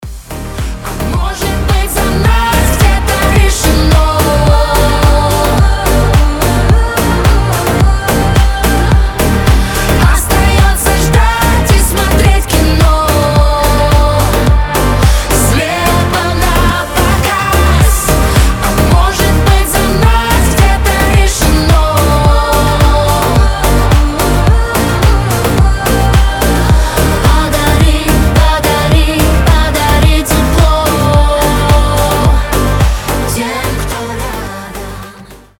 • Качество: 320, Stereo
громкие
дуэт
басы
добрые